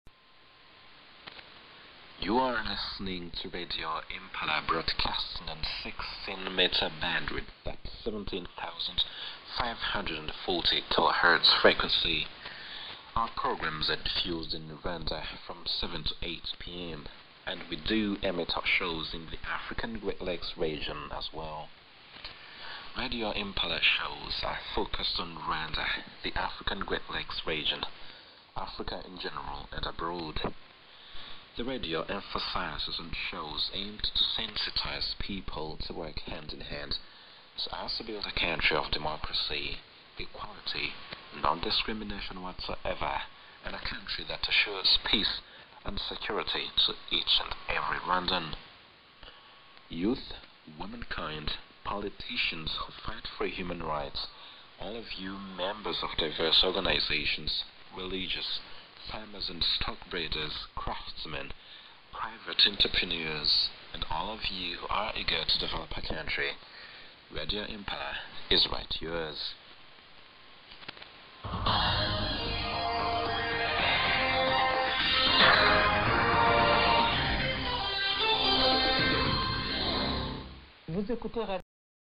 Radio Impala and Station ID Audio in English